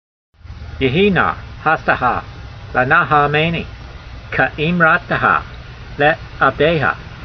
v76_voice.mp3